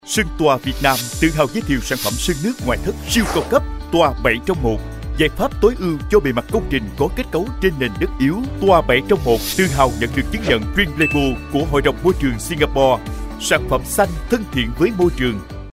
男越南1T-14
男越南1T-14 越南语男声 低沉|激情激昂|大气浑厚磁性|沉稳|娓娓道来|科技感|积极向上|时尚活力|神秘性感|素人